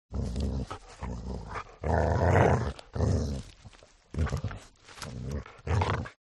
دانلود آهنگ سگ وحشی از افکت صوتی انسان و موجودات زنده
دانلود صدای سگ وحشی از ساعد نیوز با لینک مستقیم و کیفیت بالا
جلوه های صوتی